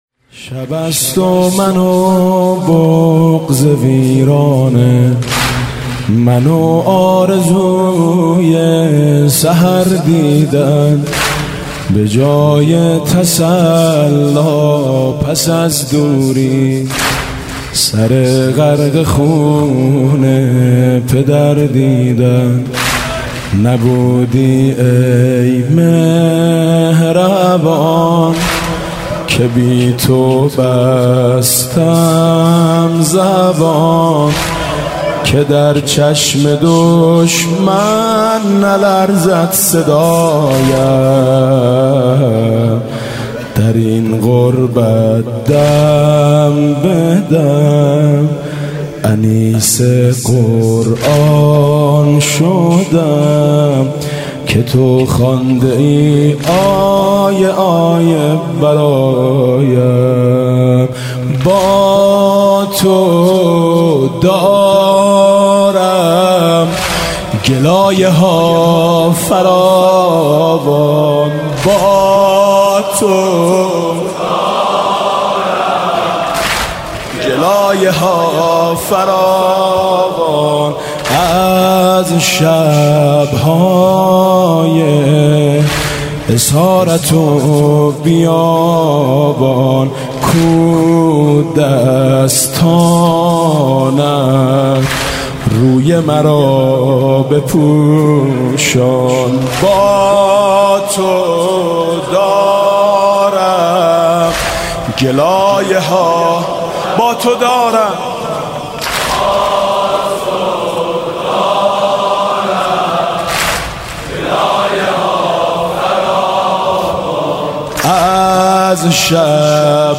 حاج میثم مطیعی
محرم 95
میثم مطیعی محرم 95 واحد